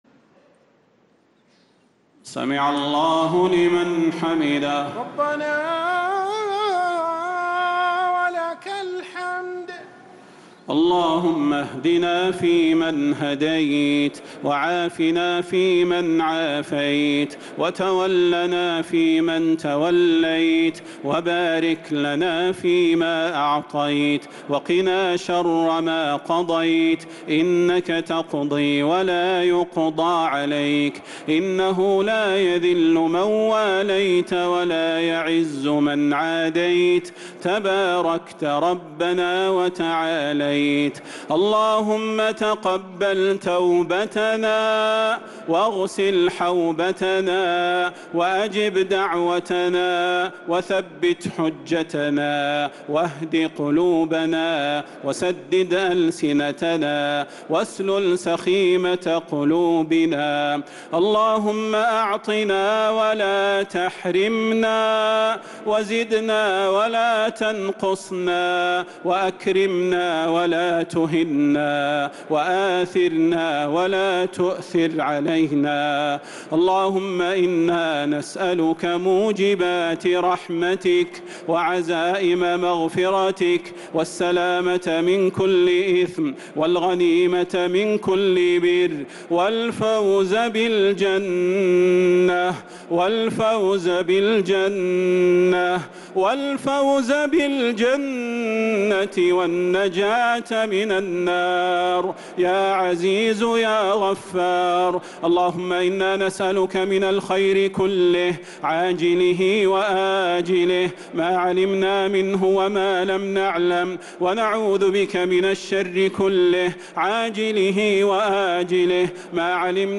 دعاء القنوت ليلة 21 رمضان 1446هـ | Dua 21st night Ramadan 1446H > تراويح الحرم النبوي عام 1446 🕌 > التراويح - تلاوات الحرمين